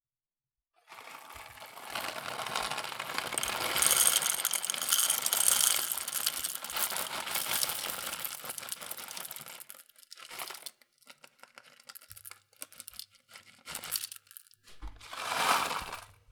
coffee beans poured in a metallic bowl.wav
Coffee beans are poured into a small metallic bowl, of a grinder. Recorded in a small kitchen with a Tascam DR 40.
coffee_pouring_fjf.wav